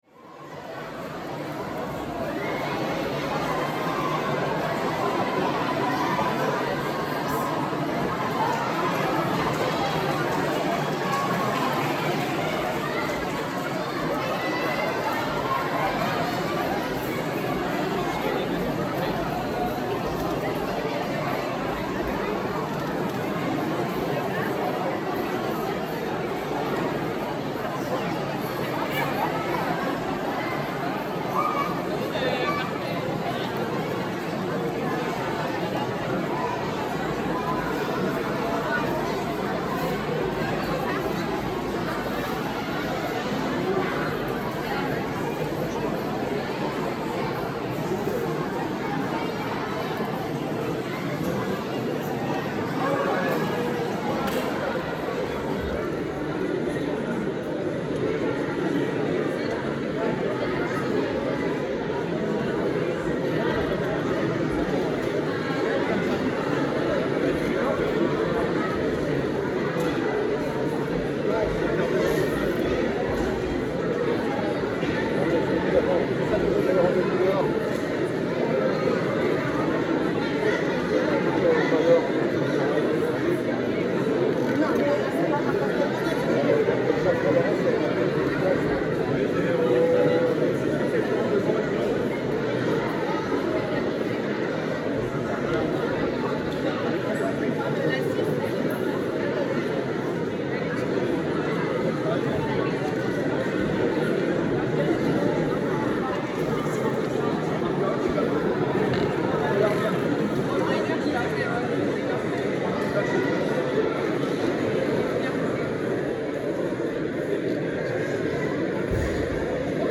Разговоры, смех и восторг толпы людей для монтажа видео в mp3
15. Большое количество людей шумит в помещении, разговаривают, веселятся
tolpa-v-pomeshenii.mp3